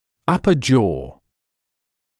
[‘ʌpə ʤɔː][‘апэ джоː]верхняя челюсть